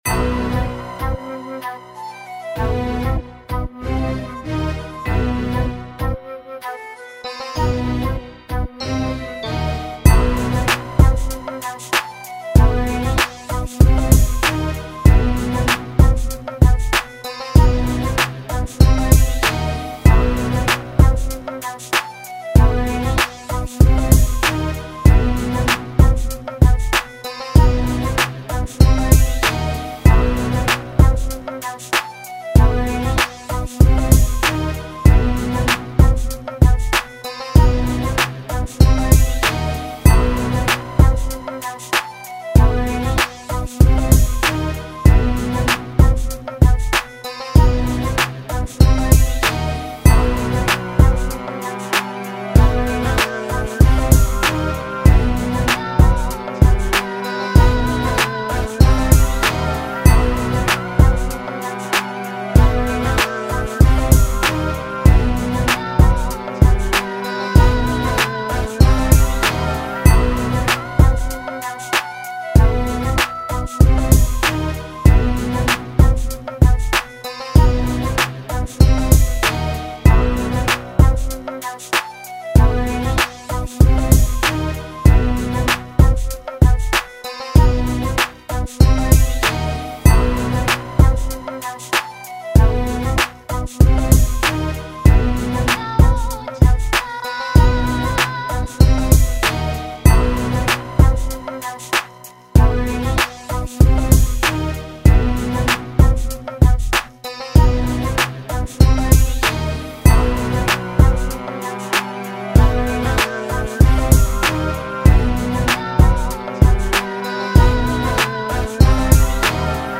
Simple melody but it works nicely.
90 BPM.